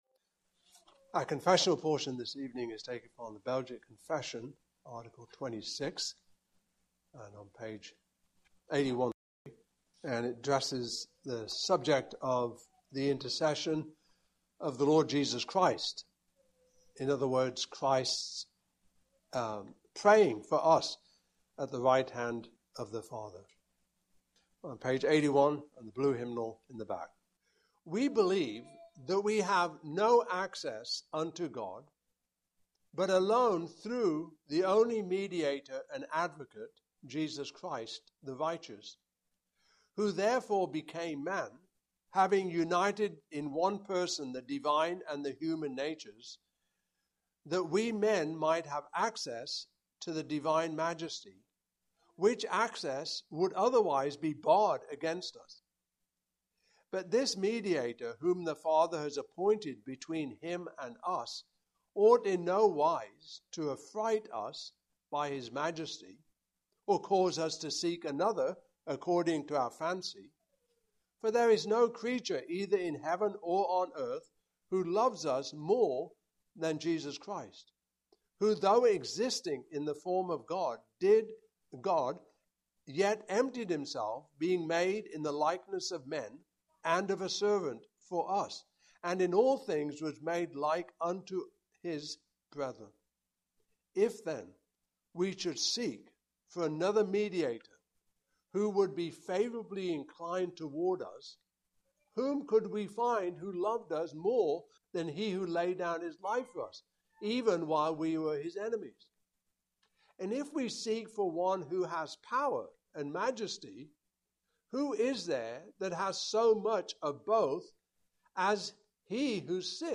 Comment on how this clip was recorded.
Passage: Hebrews 4:14-16; 7:21-28; 10:10-23 Service Type: Evening Service